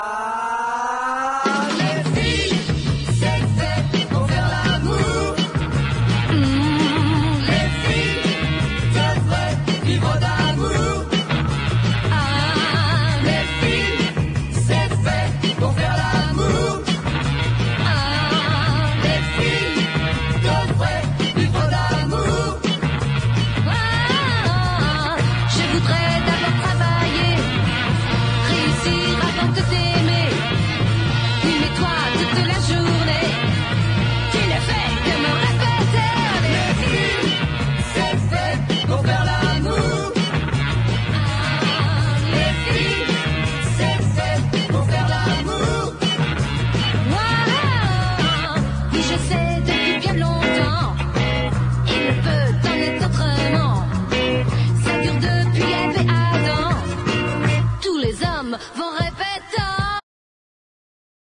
SOUL / SOUL / 60'S / RHYTHM & BLUES / MOD / POPCORN
MOD リズム&ブルース・クラシック満載！ ノース・カロライナ州グリーンズボロ出身の最強兄妹R&Bデュオ！
ソウルフルな擦れ声に心底惚れ惚れします。